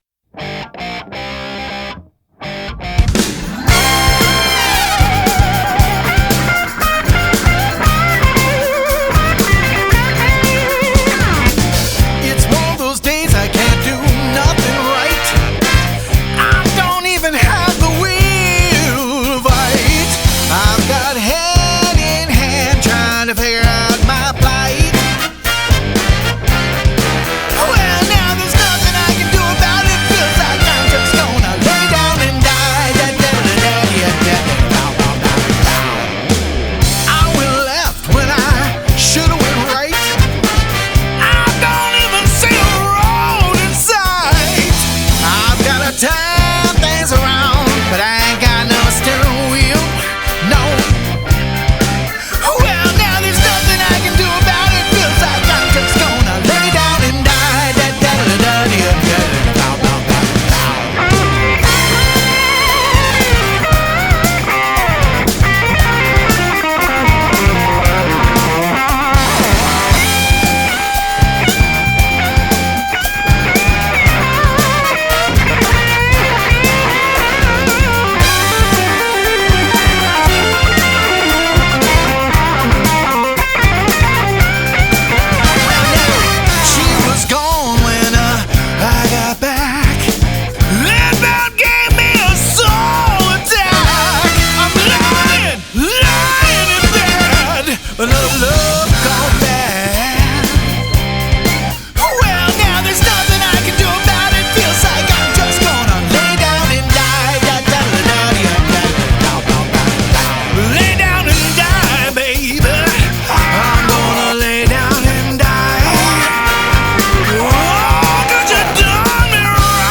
Raw, soulful, and deeply rooted in true rock ‘n’ roll
classic blues and rock vibe